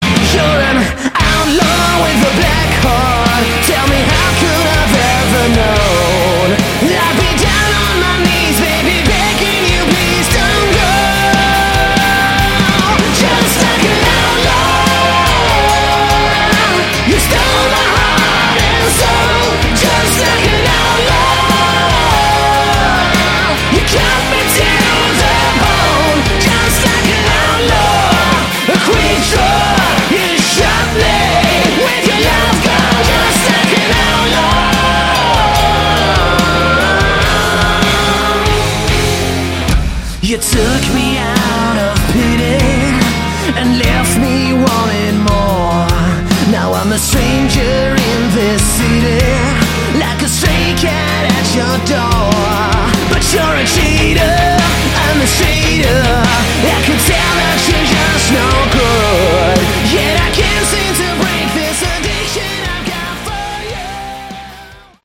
Category: Hard Rock
guitar, backing vocals